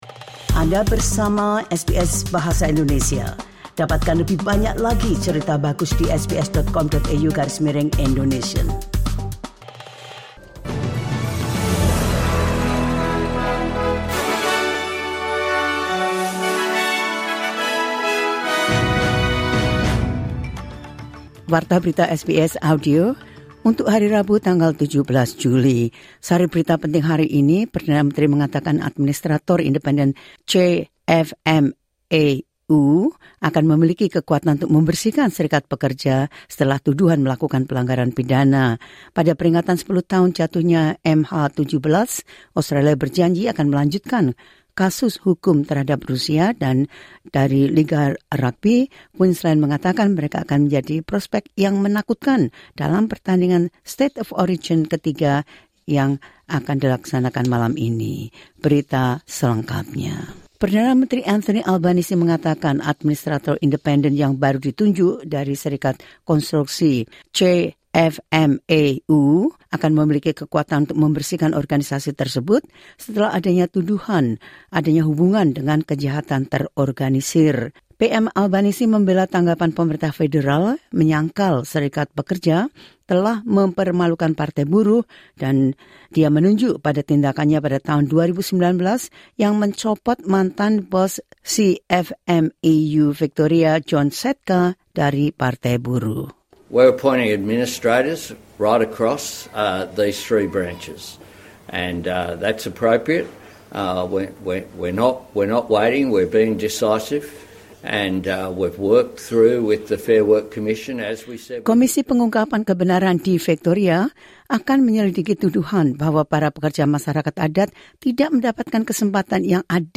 The latest news of SBS Audio Indonesian program – 17 Jul 2024.